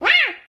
meow.mp3